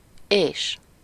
Ääntäminen
IPA: [jɑ]